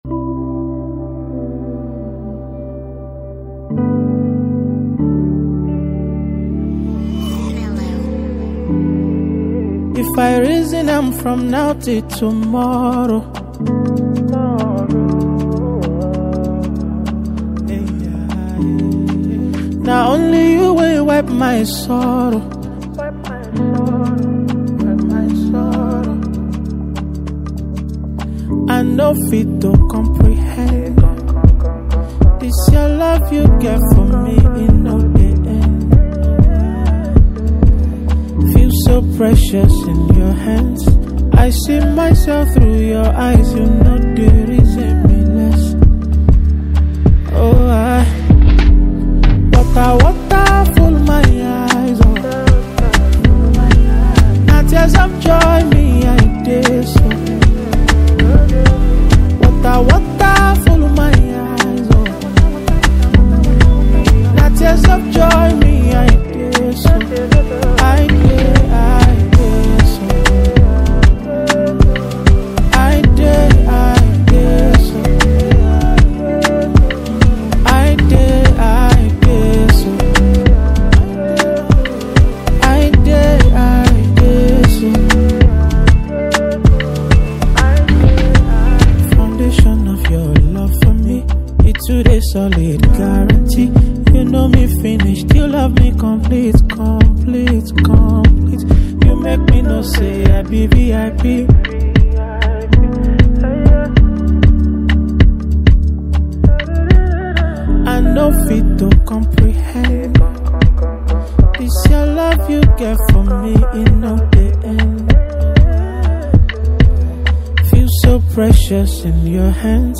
A beautiful Song of Gratitude